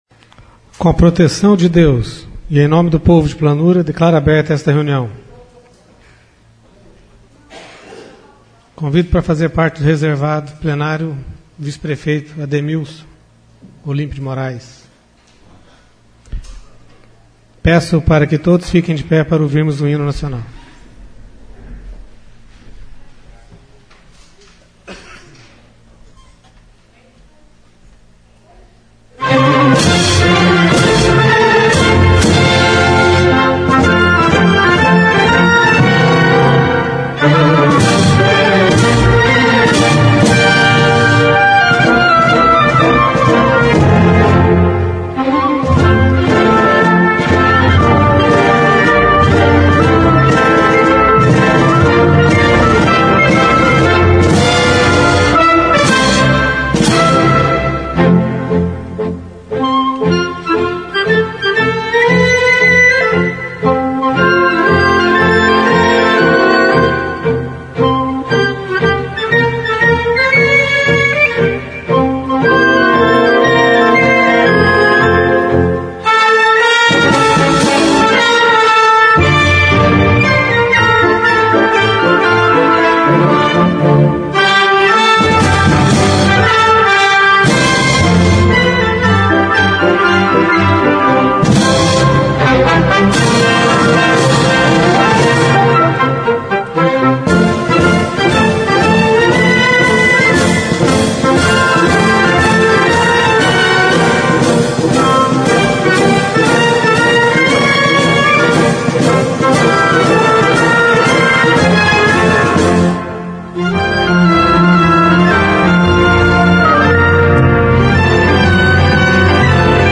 Sessão Ordinária - 15/09/14